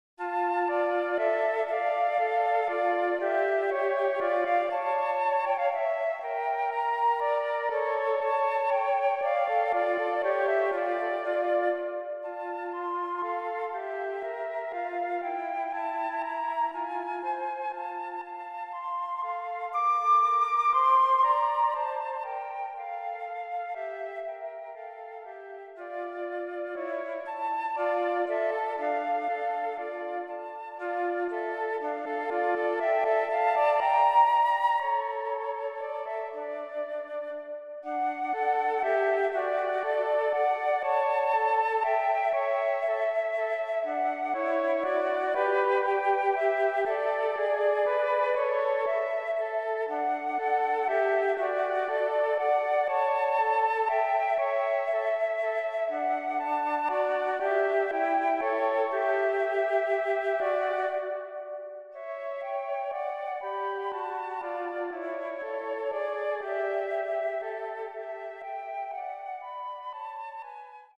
für 2 Flöten
Allegro con brio